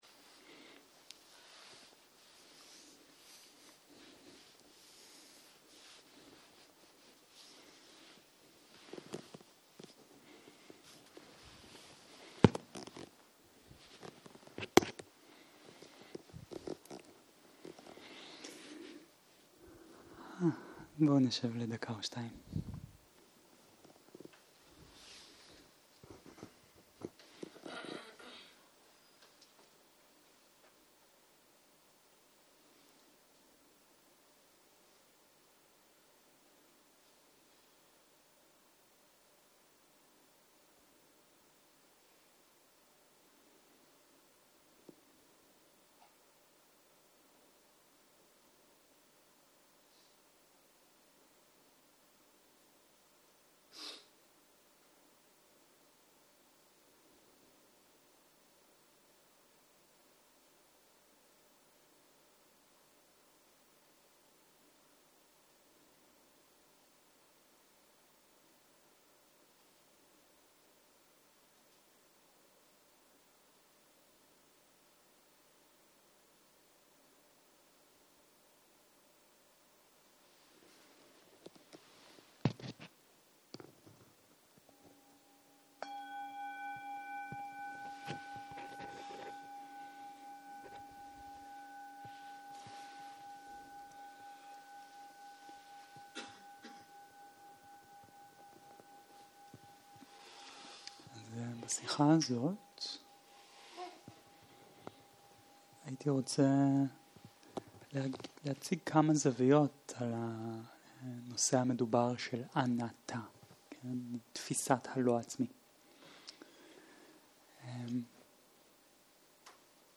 15.02.2023 - יום 8 - ערב - שיחת דהרמה - להקל על העצמי, להבין יצירת עצמיים - הקלטה 12